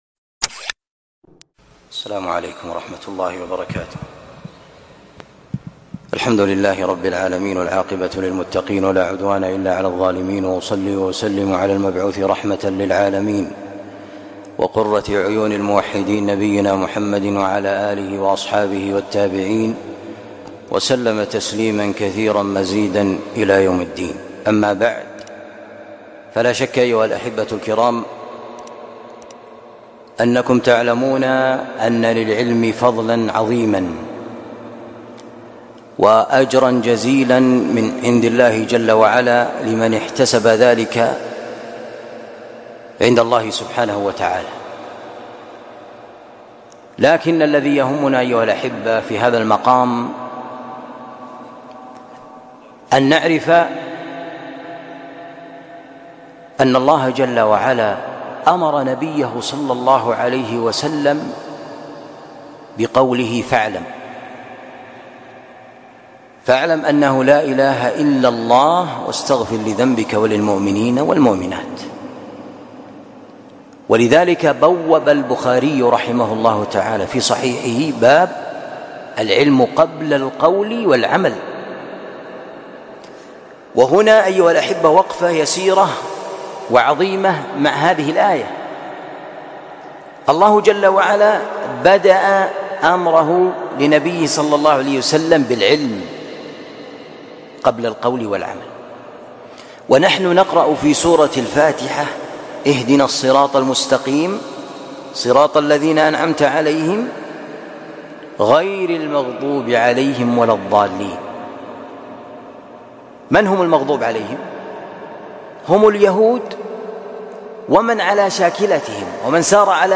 كلمة بعنوان أهمية العلم وتصحيح النية في الطلب